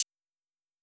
Closed Hats
Hi-Hat (Cameras).wav